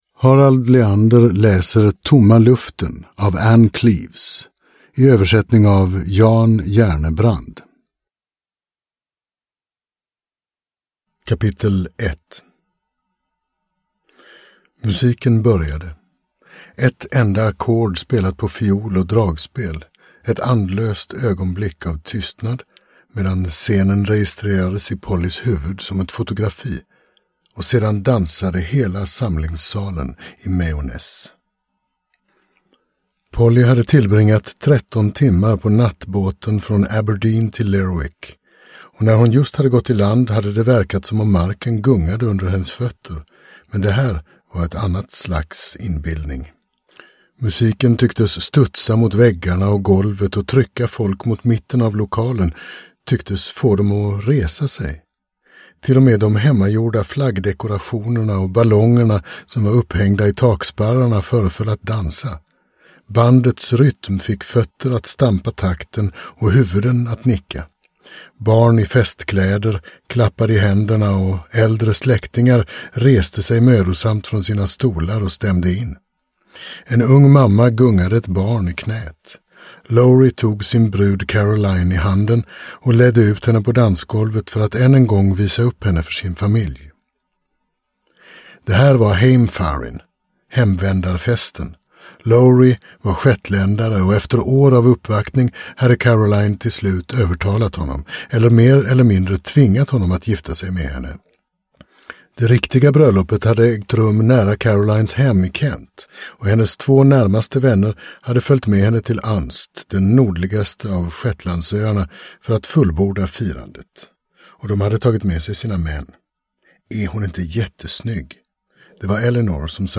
Tomma luften – Ljudbok – Laddas ner